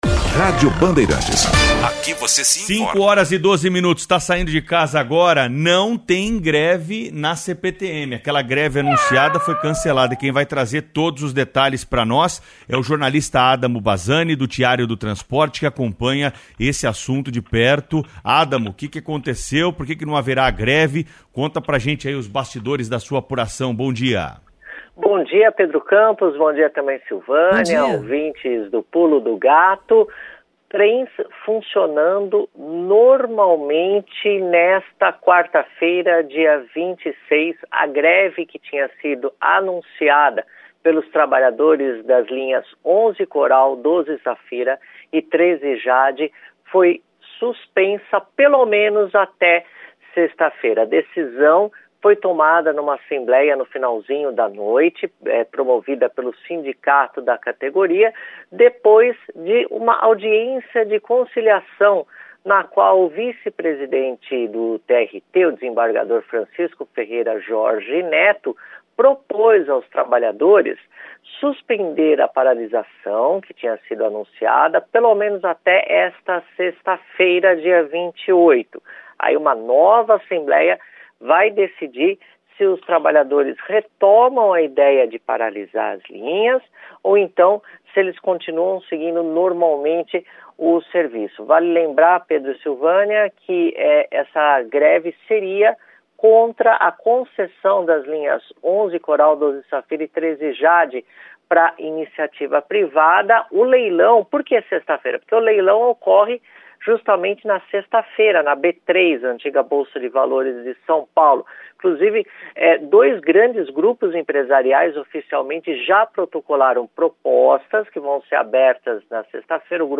☝ Na segunda metade do áudio, após a suspensão da greve na CPTM, falo ao vivo na Rádio Bandeirantes sobre os Ônibus a Biometano/GNV, inclusive *cito o veículo da Sambaíba CPTM: até 3 minutos e 15 segundos Ônibus GNV/Biometano a partir deste ponto